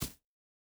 Bare Step Grass Hard E.wav